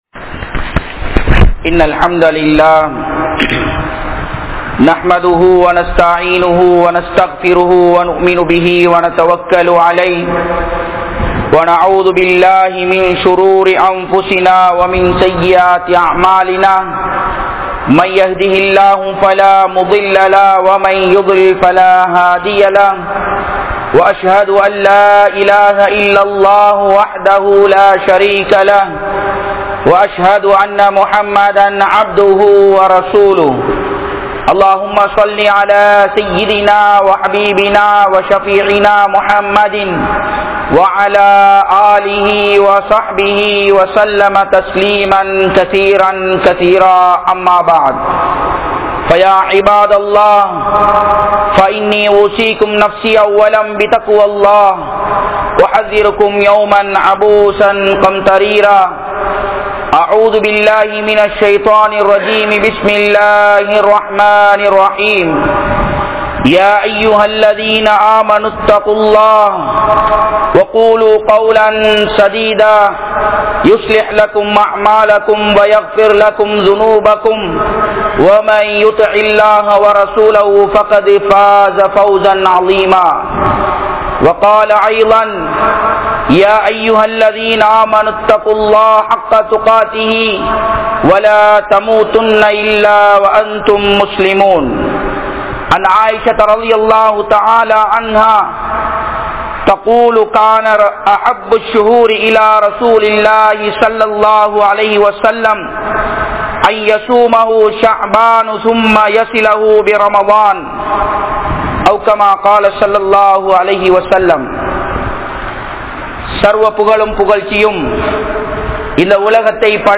Allah Mannikkaatha 02 Nafarhal (அல்லாஹ் மன்னிக்காத 02 நபர்கள்) | Audio Bayans | All Ceylon Muslim Youth Community | Addalaichenai